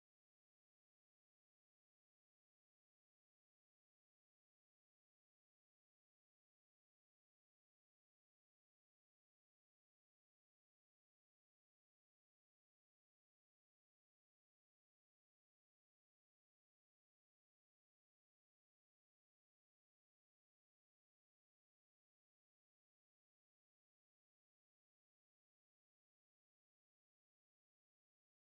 From a live webstream at The Avalon Lounge.